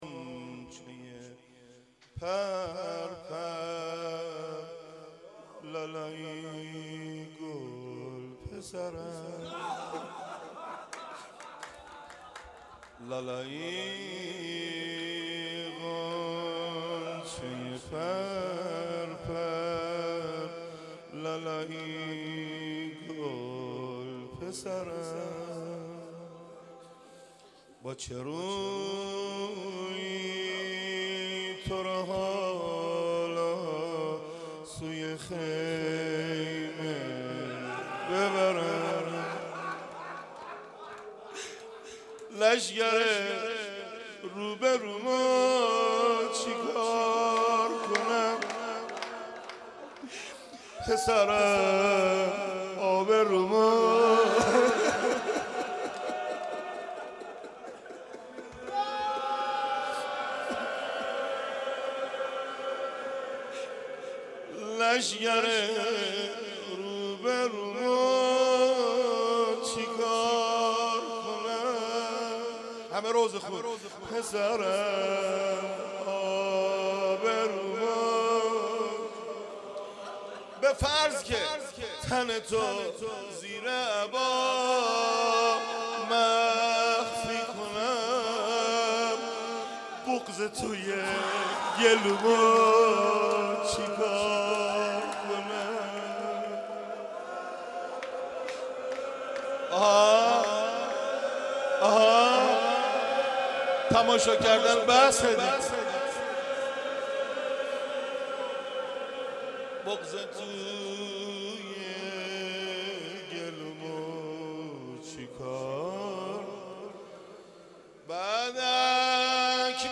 مناسبت : دهه دوم محرم